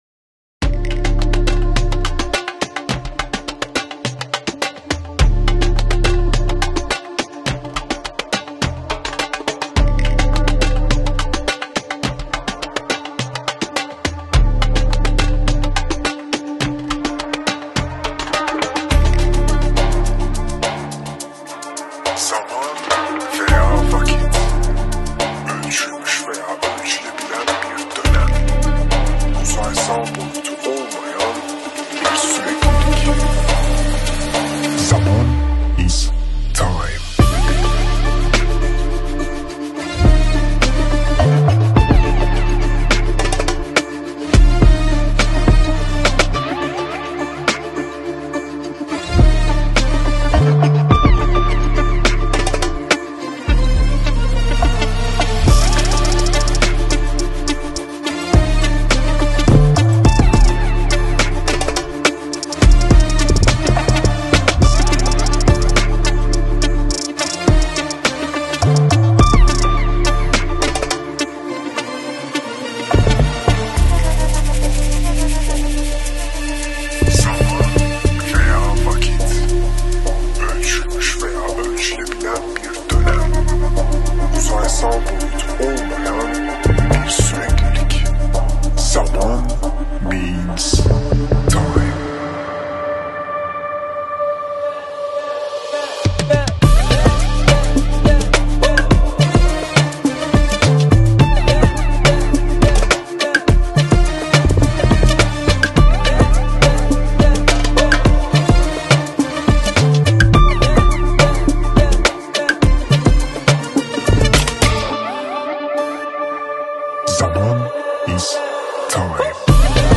Voyage immobile sur un beat Lo-Fi.
Un flow BPM lascif, envoûtant et liquoreux.